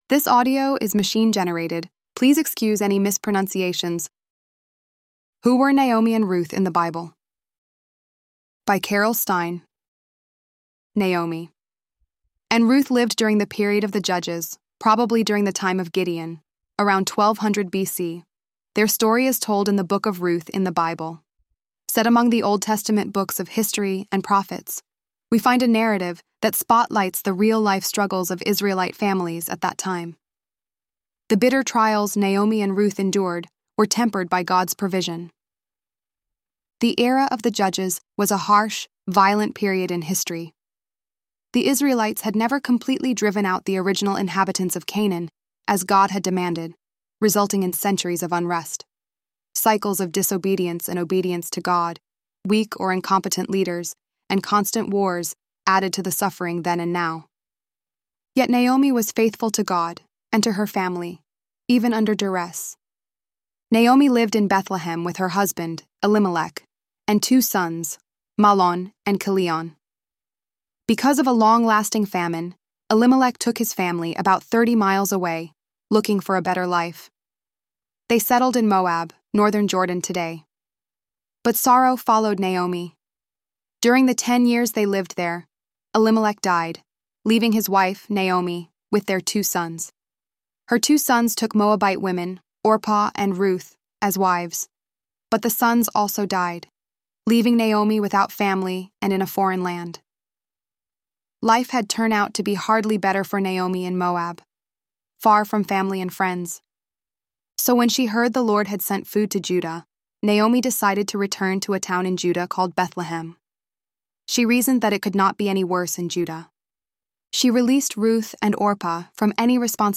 ElevenLabs_10.21.mp3